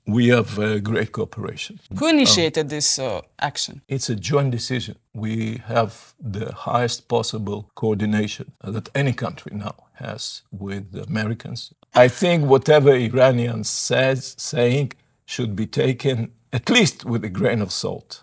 ZAGREB - Dok se cijeli svijet pita koliko će trajati rat na Bliskom istoku i kakve će globalne posljedice ostaviti, odgovore na ta pitanja potražili smo u Intervjuu tjedna Media servisa od izraelskog veleposlanika u Zagrebu Garyja Korena.